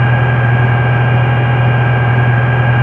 rr3-assets/files/.depot/audio/sfx/electric/mp4x_idle.wav